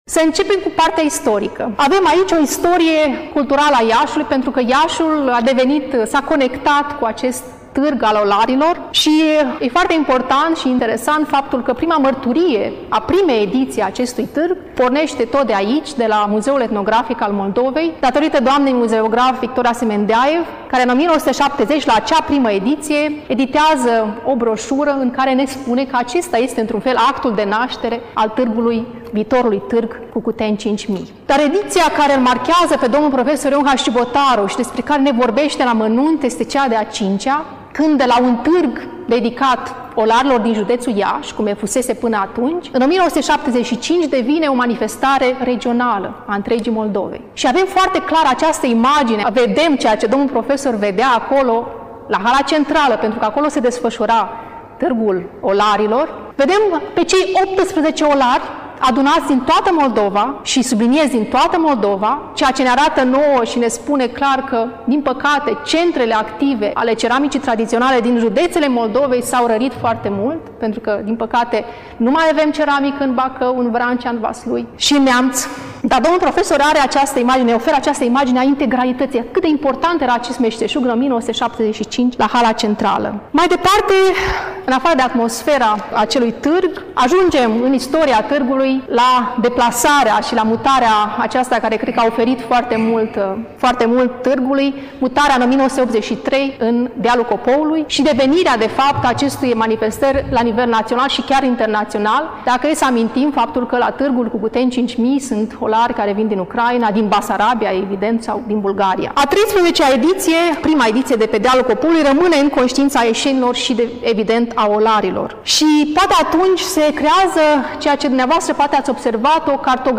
Până la final, difuzăm discursul